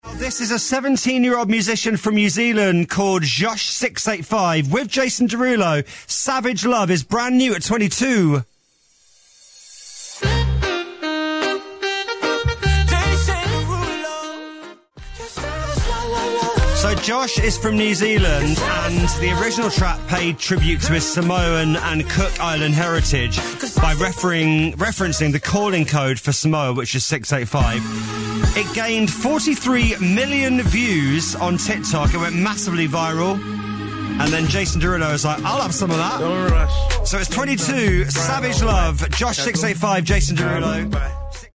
♫ Shuffle Dance Music Video